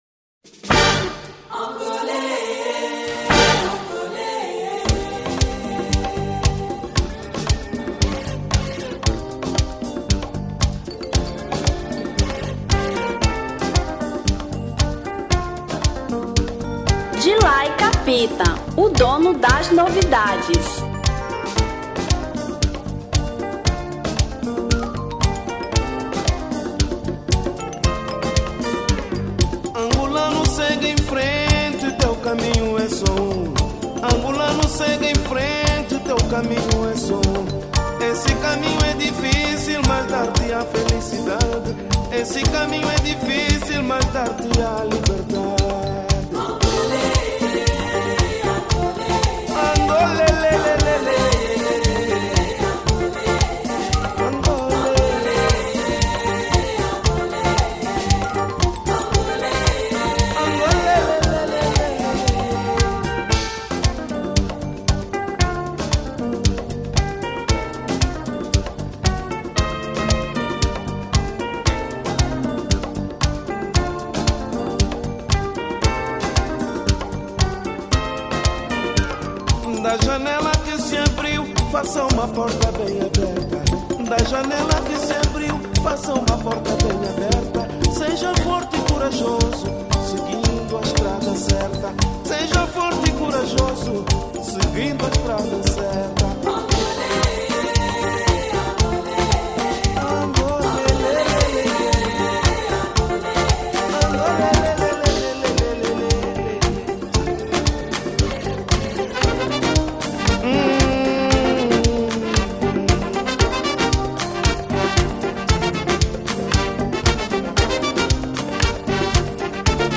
Semba 1975